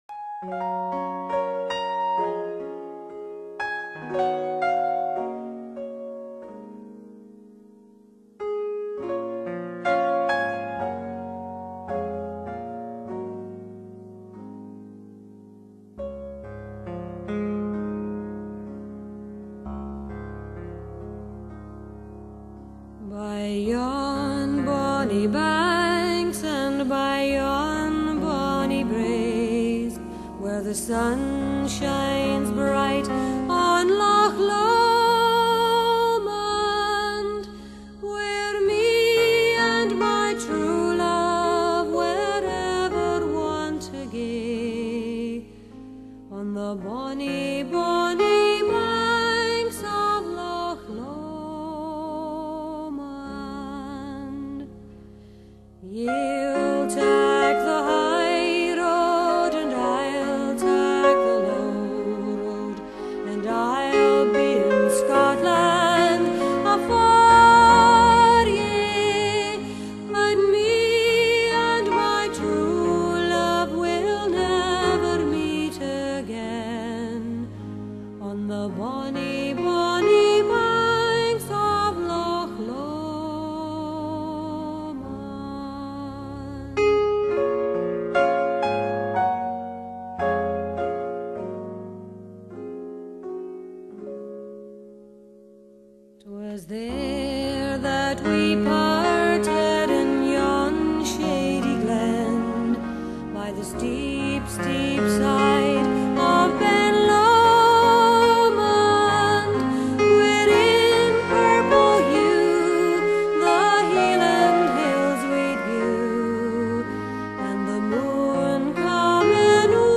本片錄音靘到極點，人聲真實肉感十足，那澄清透明的女聲迷人之至，教人捨不得關機，音場自然逼真